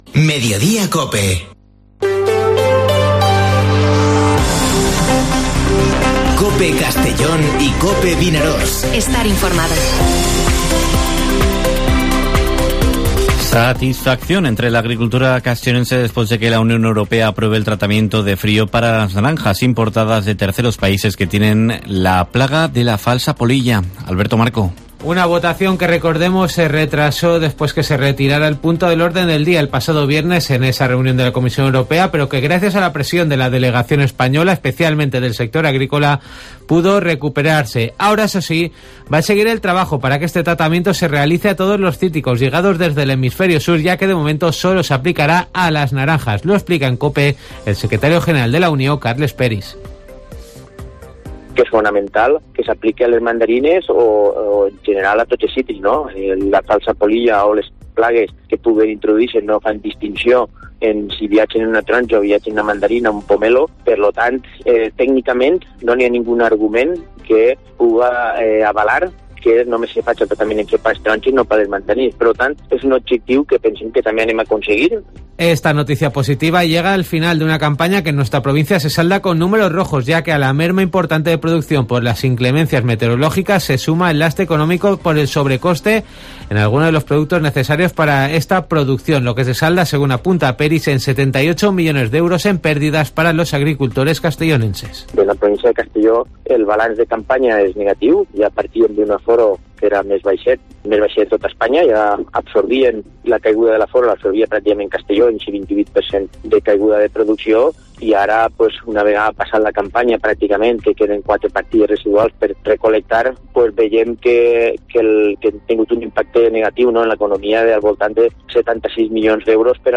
Informativo Mediodía COPE en la provincia de Castellón (26/05/2022)